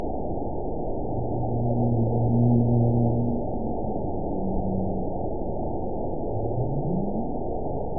event 921822 date 12/19/24 time 07:40:27 GMT (10 months ago) score 9.46 location TSS-AB02 detected by nrw target species NRW annotations +NRW Spectrogram: Frequency (kHz) vs. Time (s) audio not available .wav